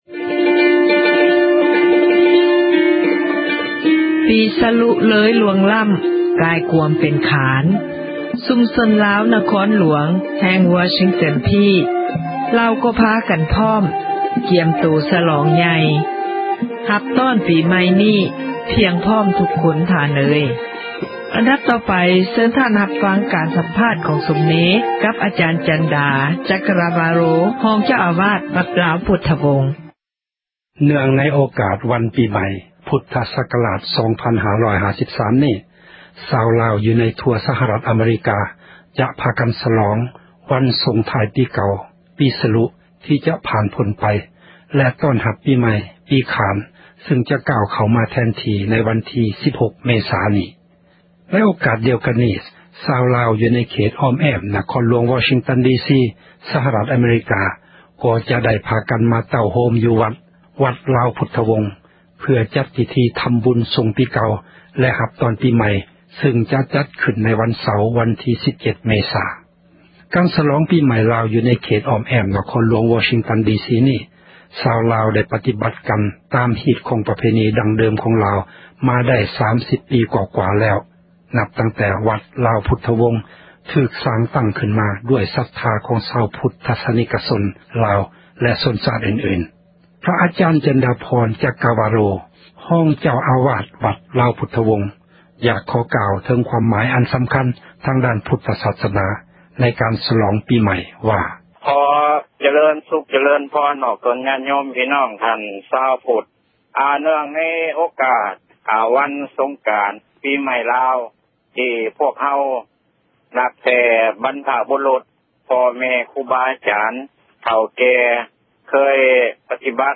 ປີໃໝ່ລາວ ທີ່ວັດລາວພຸທວົງ ວໍຊິງຕັນດີຊີ ສະຫະຣັດ ອະເມຣິກາ